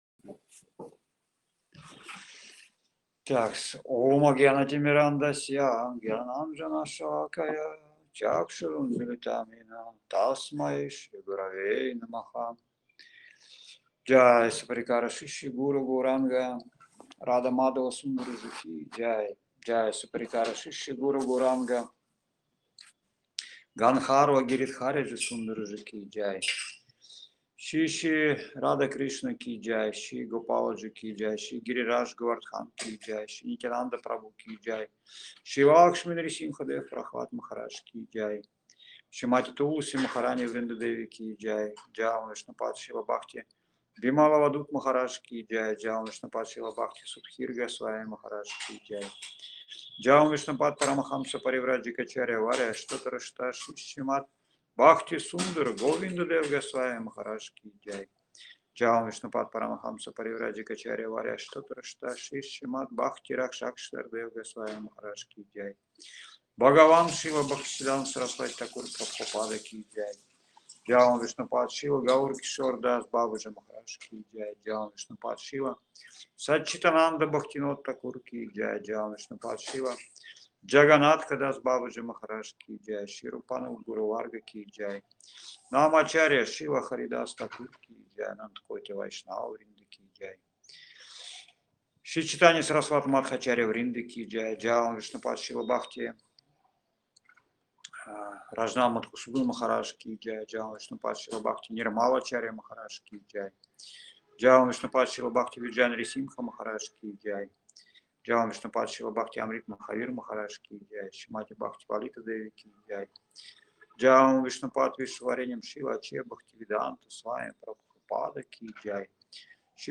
Маяпур, Индия
Лекции полностью
Киртан
Бхаджан